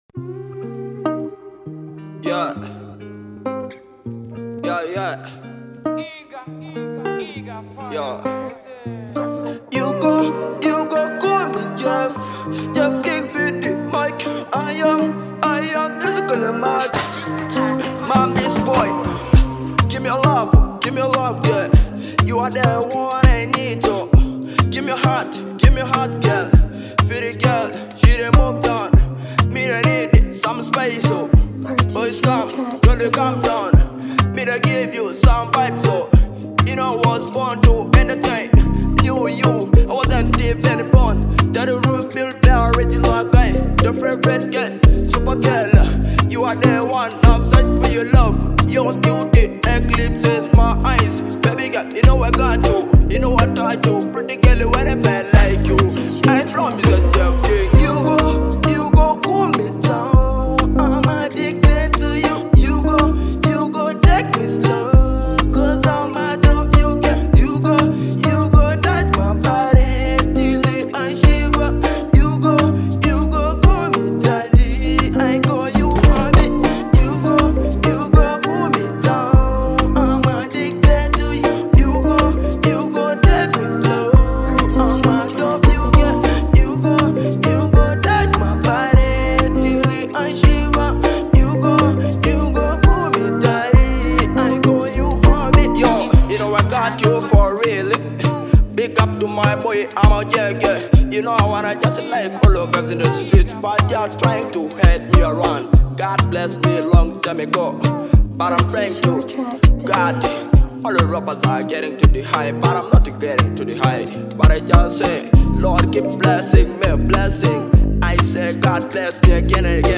Genre: Afro Pop